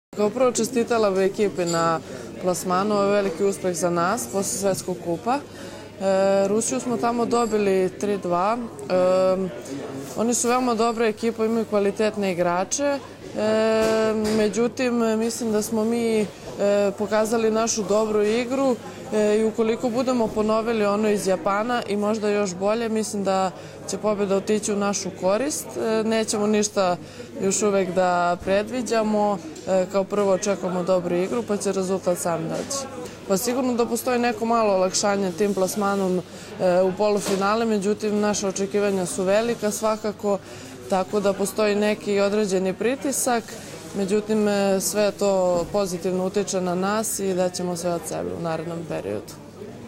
IZJAVA BJANKE BUŠE